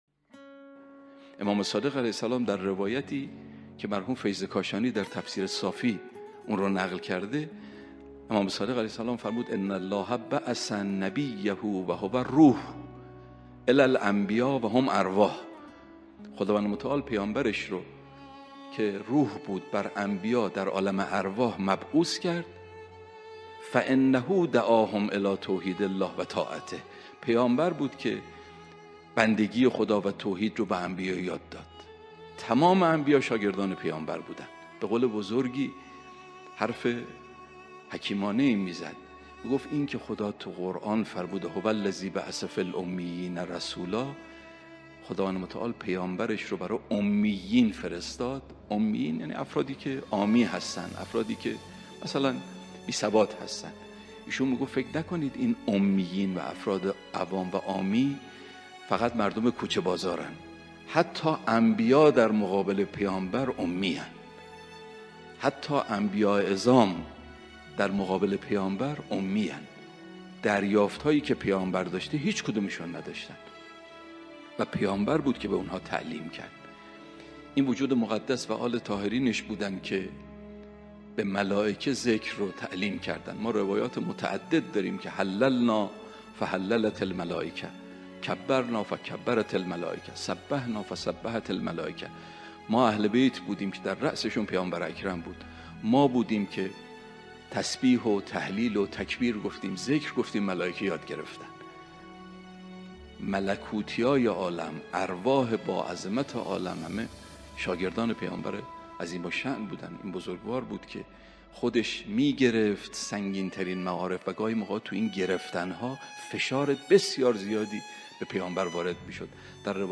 آخرین خبر/سخنرانی بسیار شنیدنی درباره پیامبر اکرم(ص